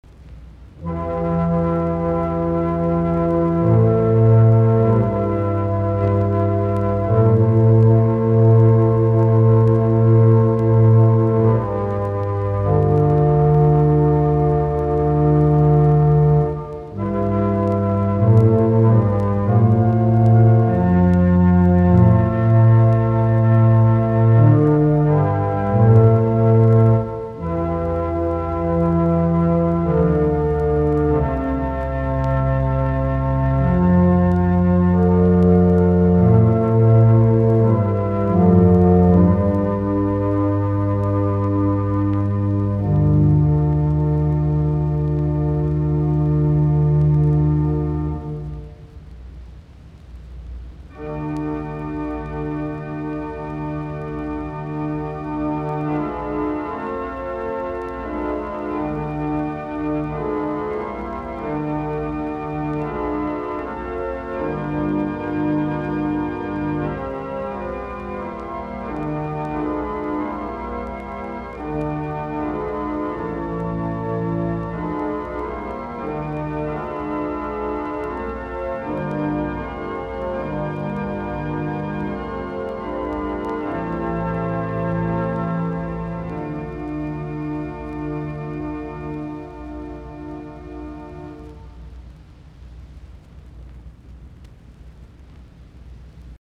Soitinnus: Kuoro, urut.